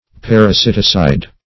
Meaning of parasiticide. parasiticide synonyms, pronunciation, spelling and more from Free Dictionary.
Search Result for " parasiticide" : The Collaborative International Dictionary of English v.0.48: Parasiticide \Par`a*sit"i*cide\ (p[a^]r`[.a]*s[i^]t"[i^]*s[imac]d), n. [Parasite + L. caedere to kill.]